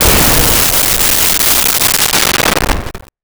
Explosion 01
Explosion 01.wav